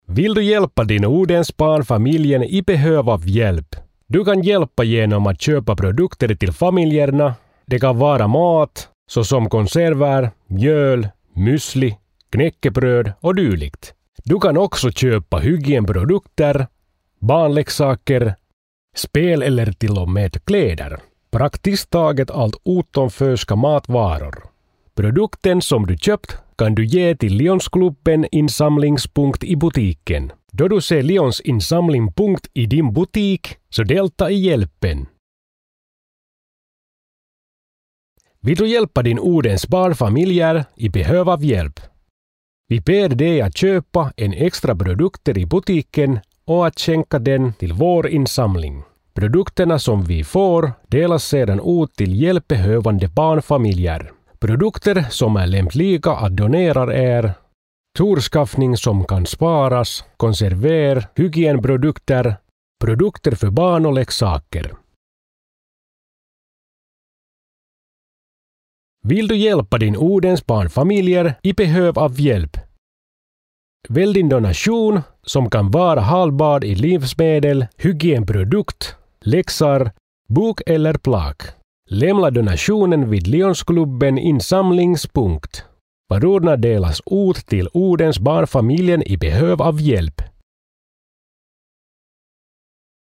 • UUTTA! Myymäläkuulutuksia (mp3) (